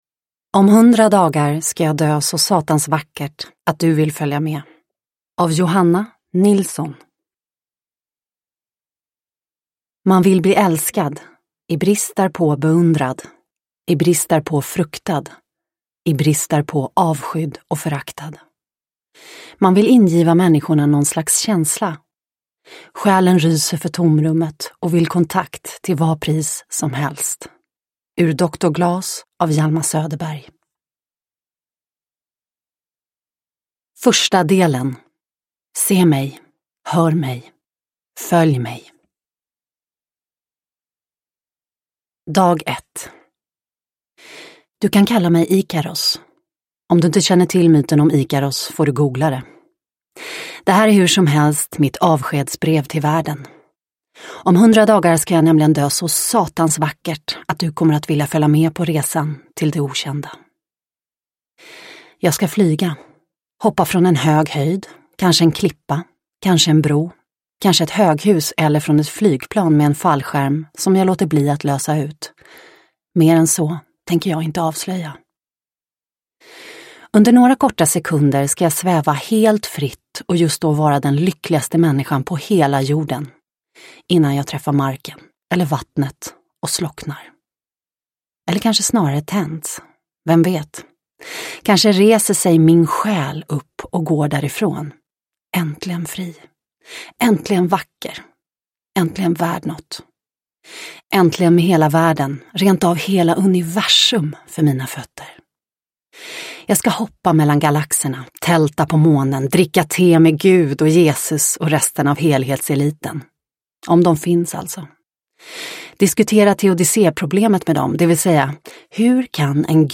Om hundra dagar ska jag dö så satans vackert ... – Ljudbok – Laddas ner